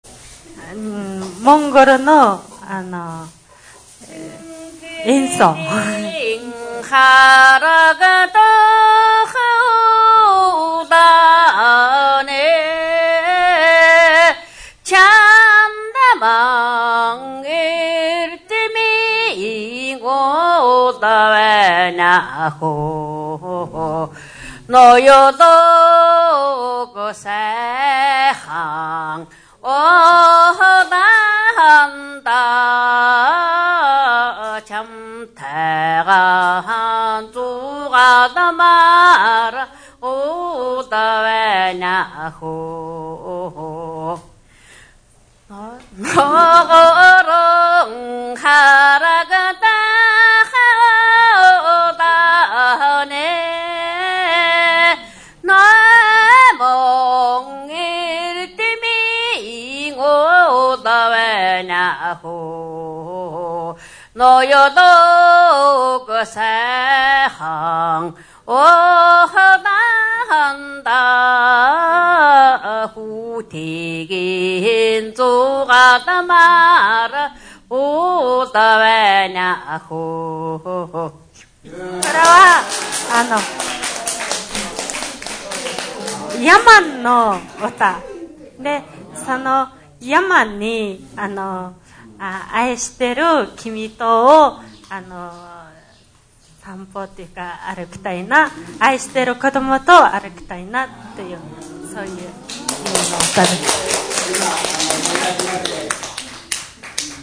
モンゴルの童謡）が堂内に響きました。